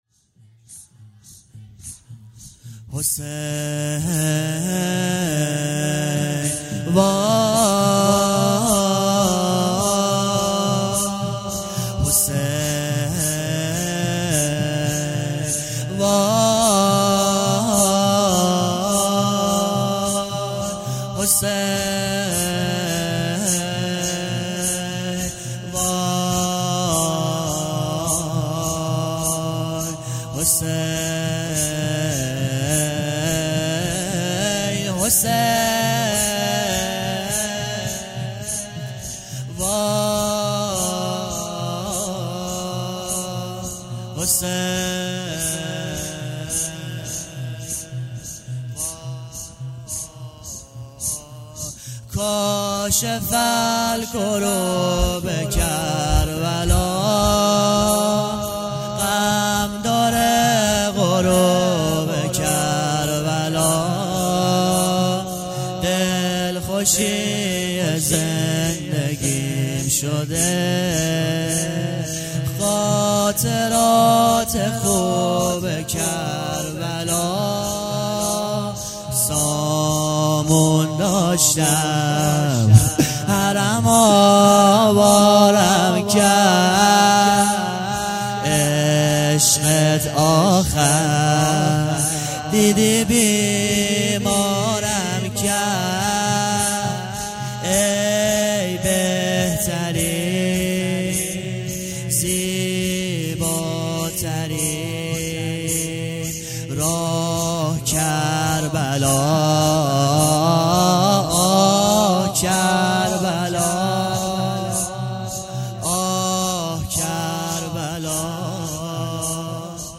محرم ۱۴۰۳